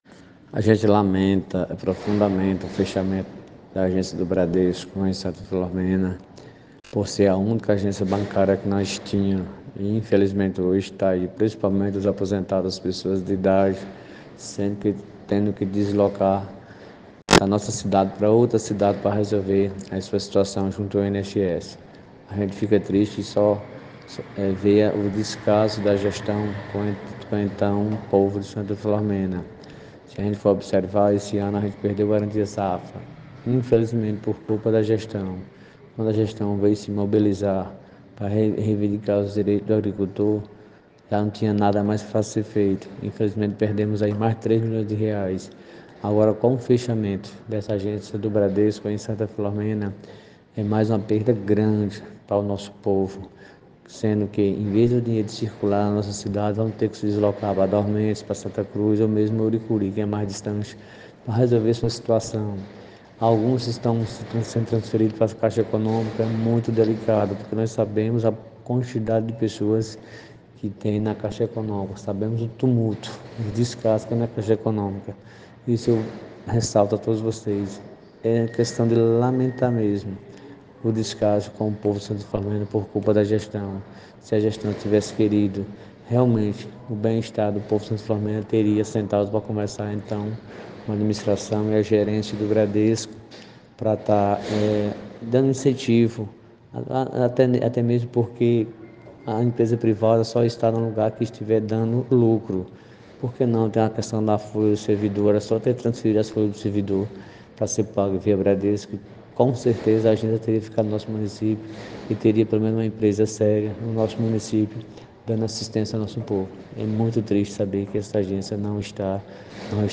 O vereador Reisinho fez um pronunciamento na câmara de vereadores lamentando a situação e cobrando da gestão que tome providencias no sentido de levar outra agência bancária pra cidade.
Ouça o que falou o vereador